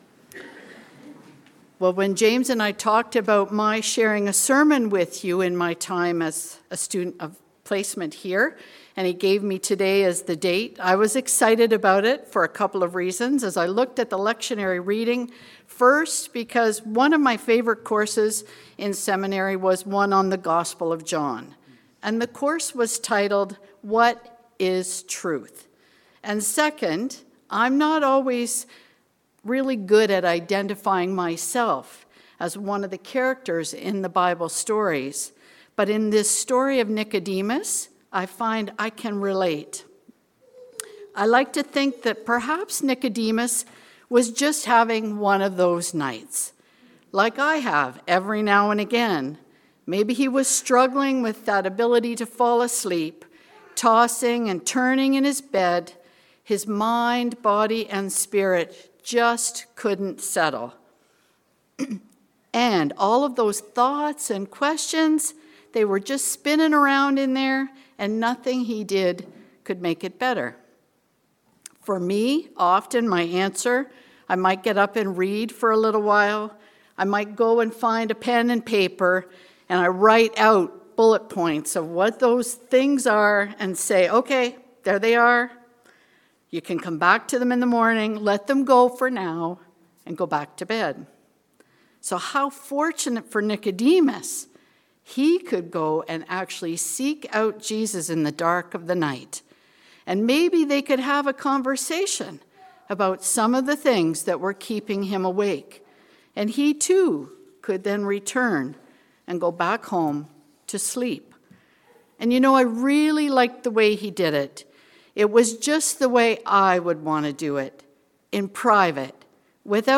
Nicodemus has questions. A sermon on the Second Sunday in Lent